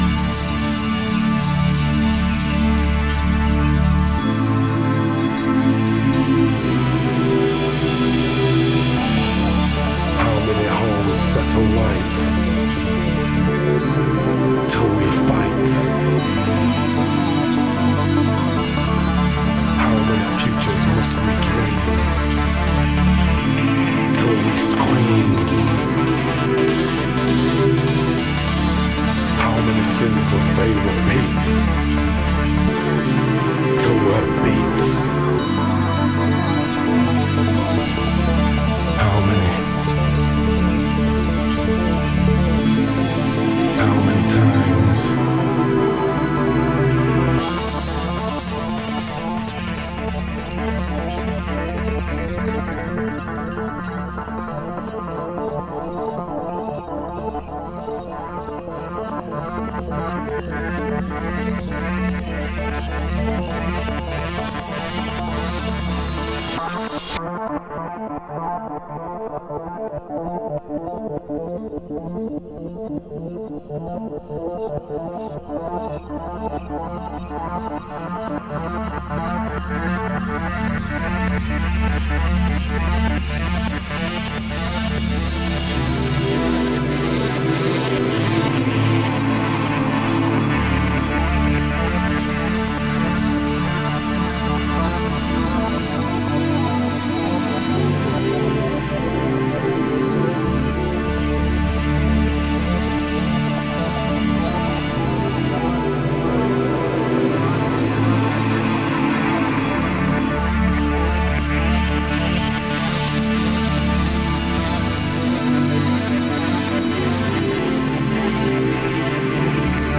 Styl: Techno Vyd�no